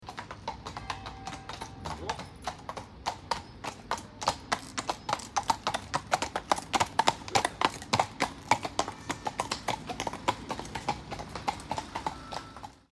Звуки копыт лошади скачать бесплатно
Звуки с топотом копыт лошадей при галопе и спокойном шаге по разным поверхностям.
kopyta-loshadi-e.mp3